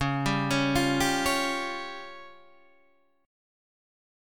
C# Major Flat 5th